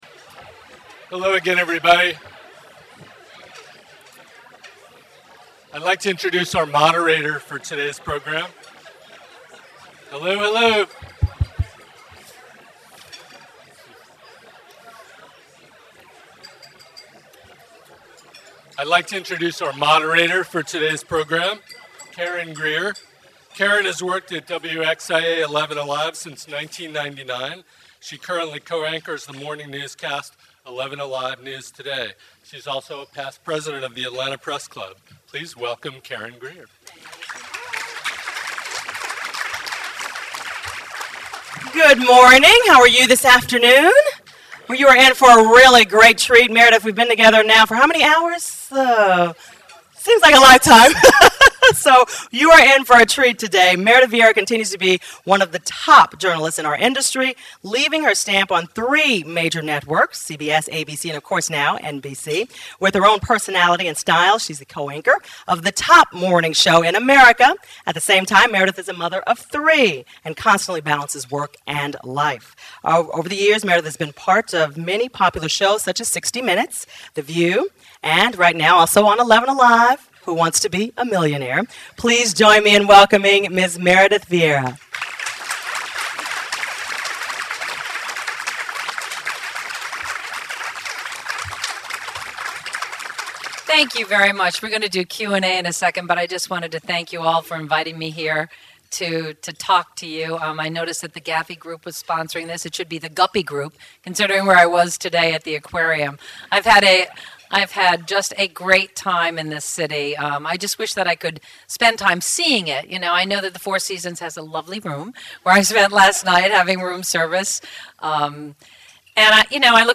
Newsmaker Luncheon - Meredith Vieira, Co-Anchor of The Today Show | Georgia Podcast Network
Show notes Meredith Vieira, Co-Anchor of Today Show, Speaks to the Atlanta Press Club on February 12, 2008.